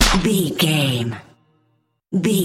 Aeolian/Minor
drum machine
synthesiser
drums